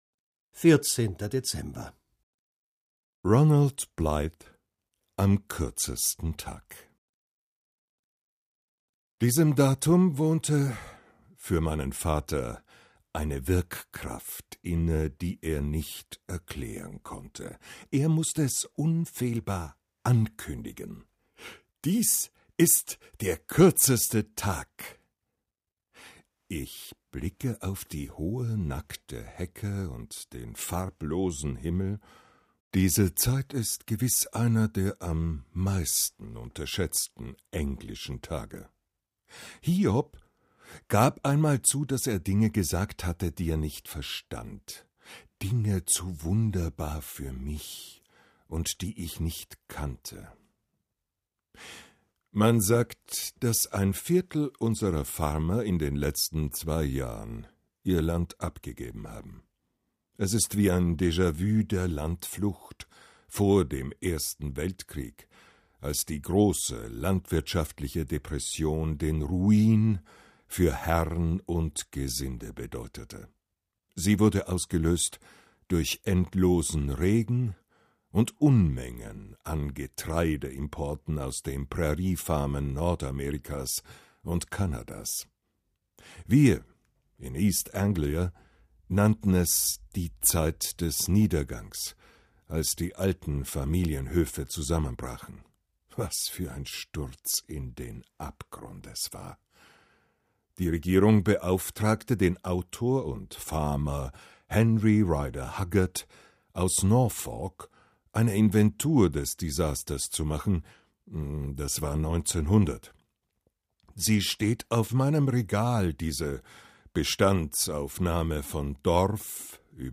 Heute ist zwar nicht der kürzeste Tag, aber wir hören uns trotzdem die gleichnamige Geschichte darüber an!